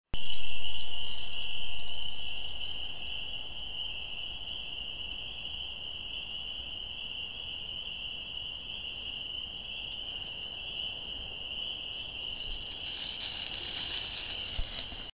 Instead, this year, I got a little recording gadget for my iPod and captured aural rather than visual representations.
the calls are soft, splended, and beautiful.
These samples are from a small vernal pool on a cool evening well past the peak of the season.
peepersSoft.mp3